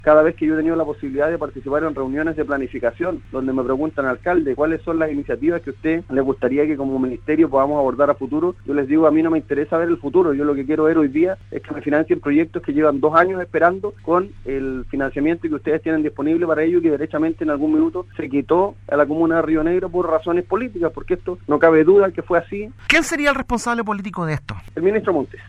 En conversación con el programa “Primera Hora” de Radio Sago, el edil de la comuna, Sebastián Cruzat, recordó que hace más de dos años, el Ministerio de Vivienda bajó sorpresivamente el financiamiento de las que iban a ser las obras de reposición del parque La Toma.